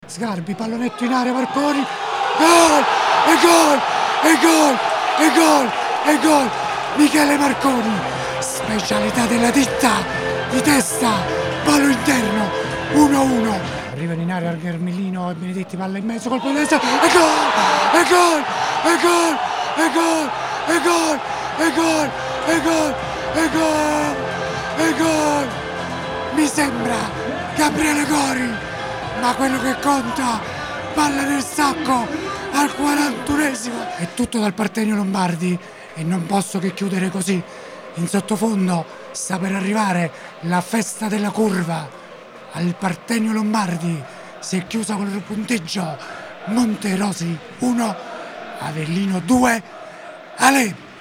Gol Avellino-Monterosi 2-1 con la Radiocronaca